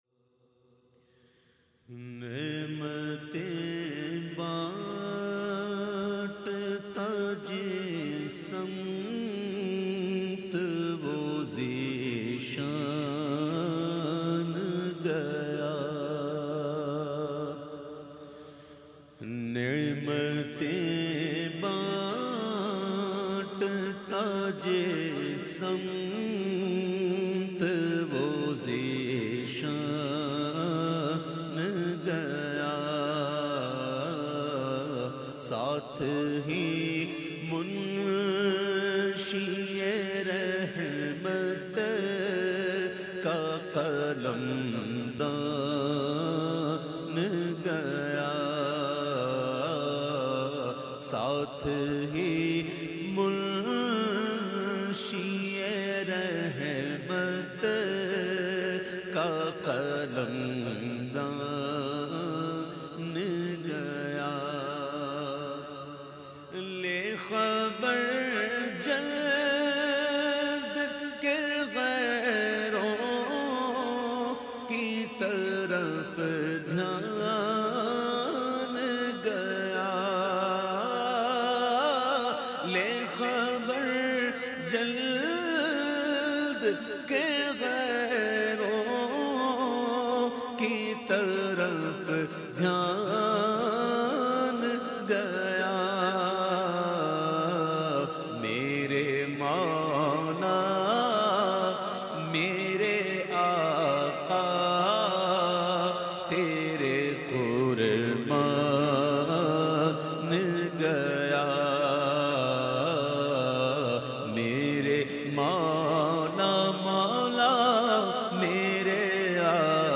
The Naat Sharif Naimatein Banta Jis Simt recited by famous Naat Khawan of Pakistan Owais Qadri.